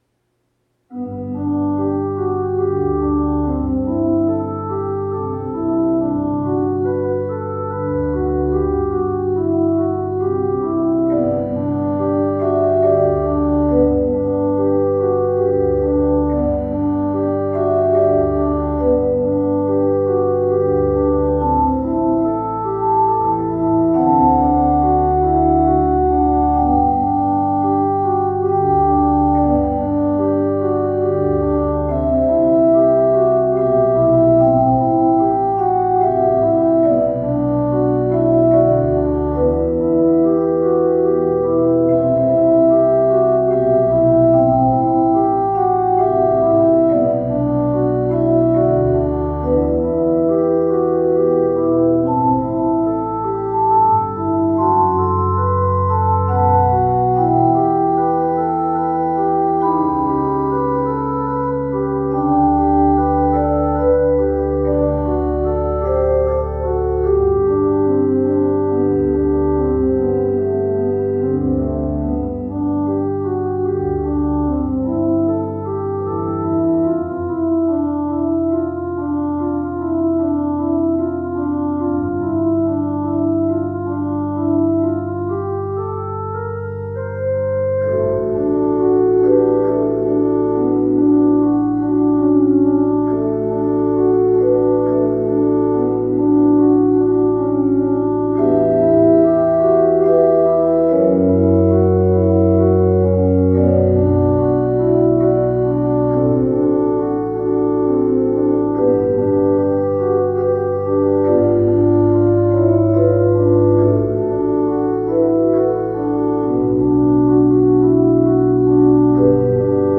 I think I would like to have this played in our ward next Christmas and play the flute part with my violin.😊 So peaceful.